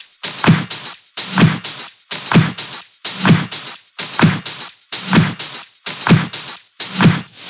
VFH3 Mini Kits Drums